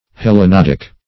Search Result for " hellanodic" : The Collaborative International Dictionary of English v.0.48: Hellanodic \Hel`la*nod"ic\, n. [Gr.